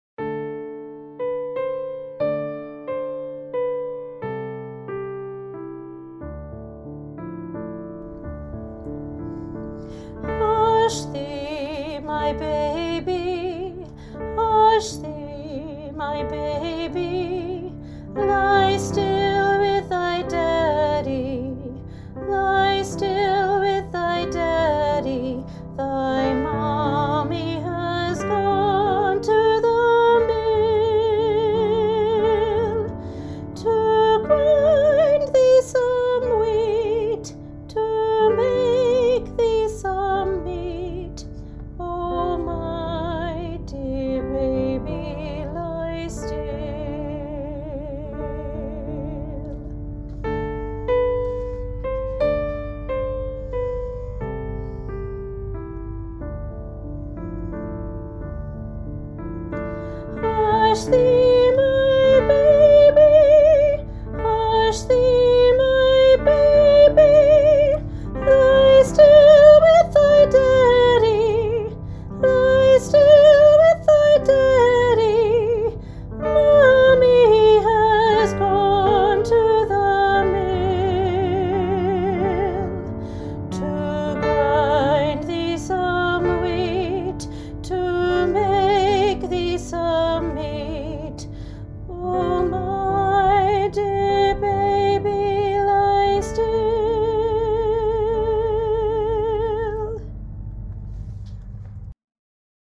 Junior Choir – Hush Thee My Baby, Part 1 – National Boys Choir of Australia
Junior Choir – Hush Thee My Baby, Part 1